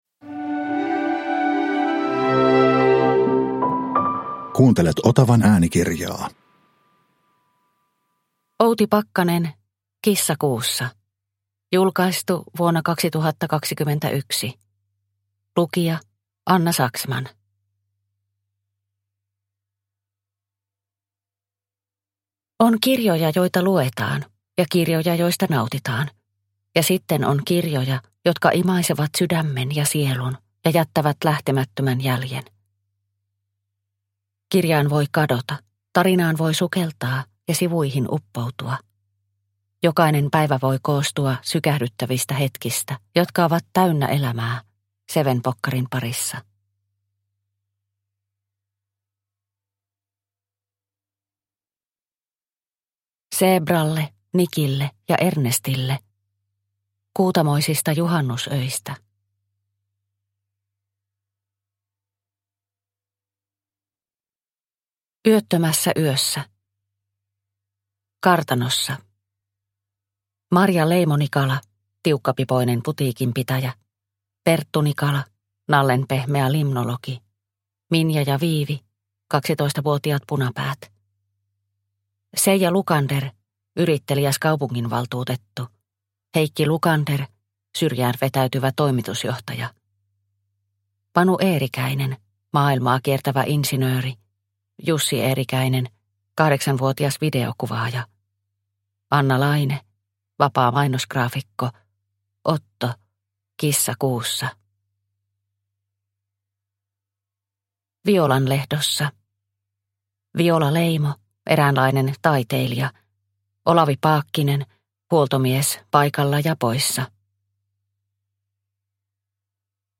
Kissa kuussa – Ljudbok – Laddas ner